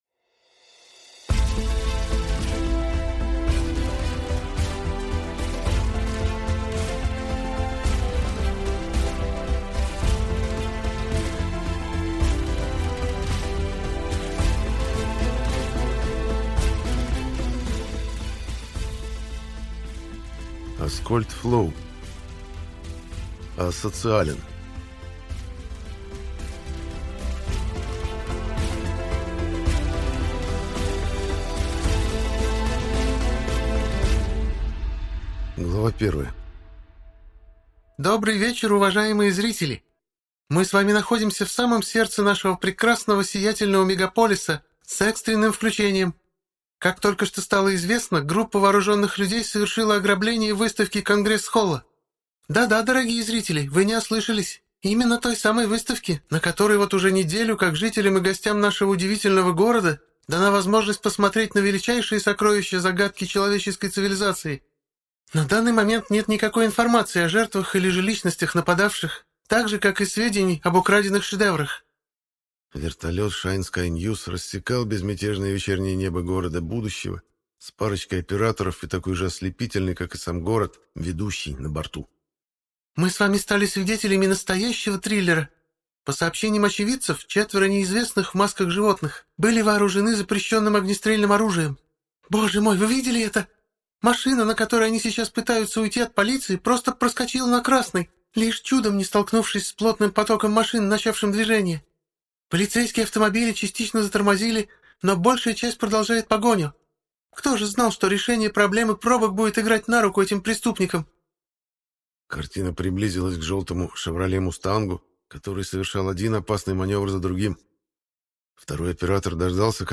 Аудиокнига Асоциален | Библиотека аудиокниг